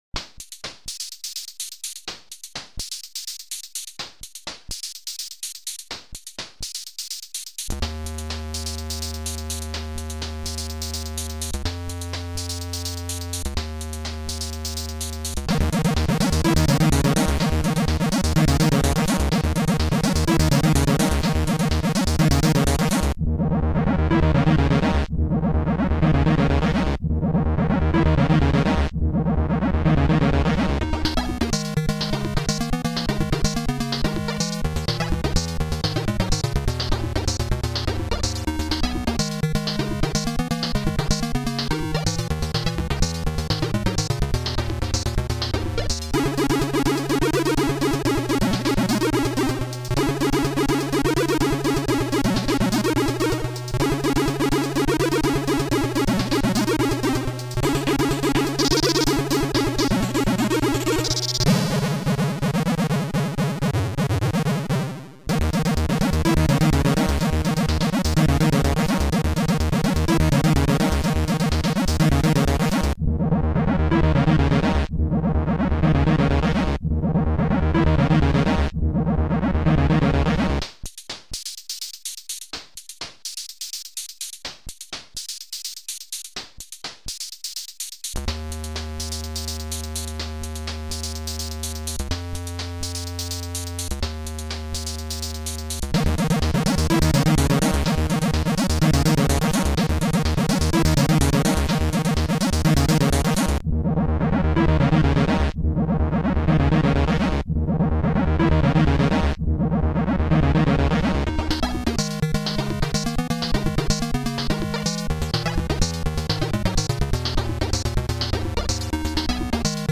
Almost-authentic C64 chiptune; is mainly a test of what GoatTracker can do.